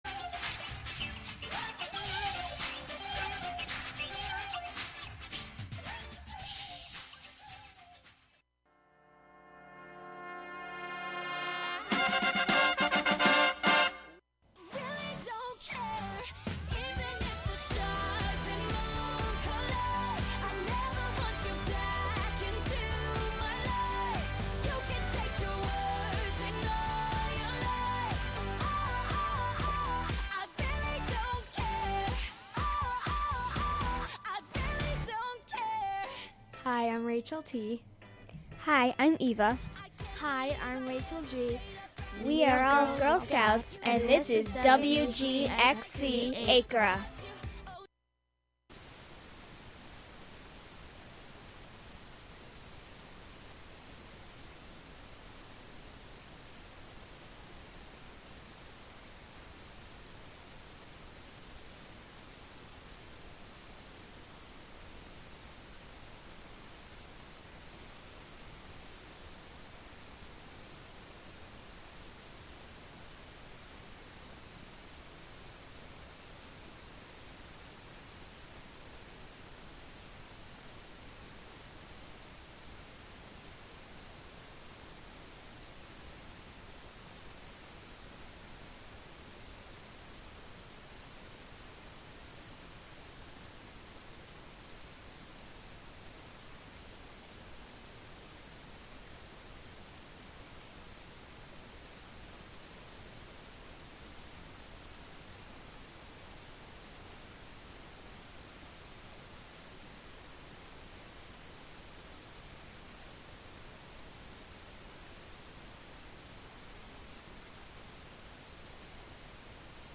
Show includes local WGXC news at beginning and midway through.